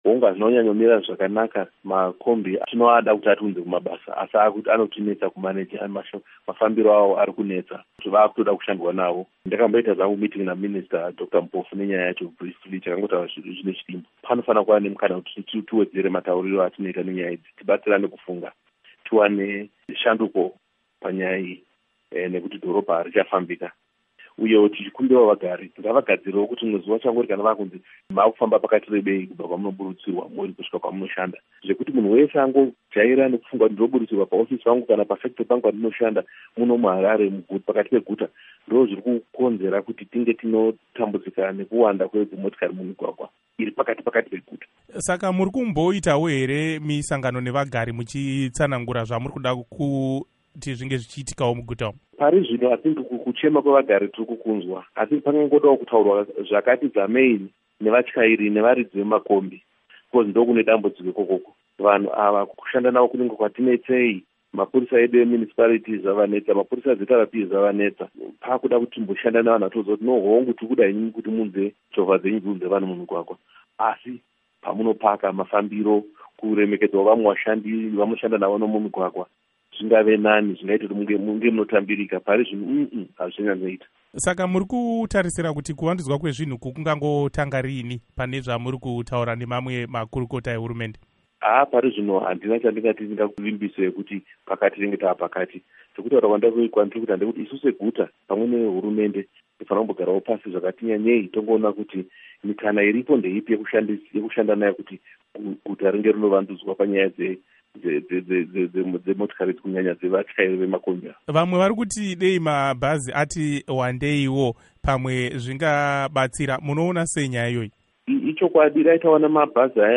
Hurukuro naVaBernard Manyenyeni